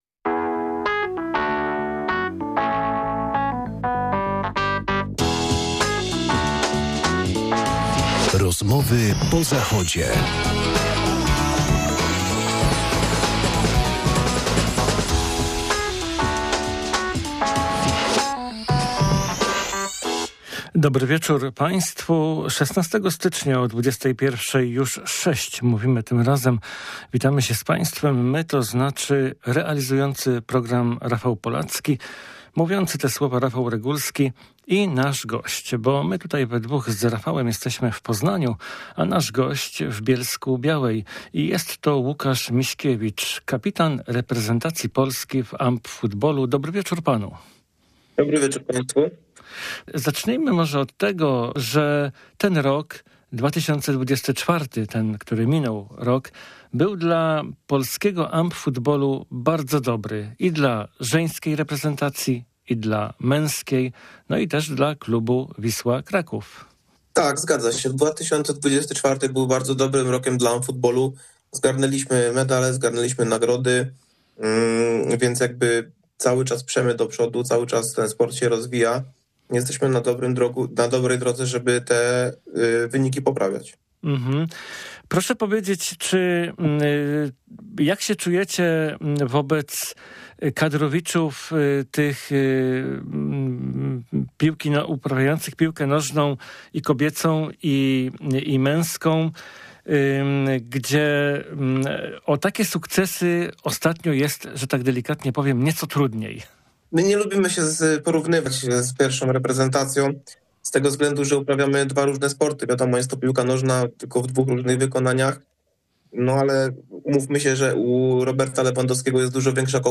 Tym razem gościem (na odległość) wieczornej audycji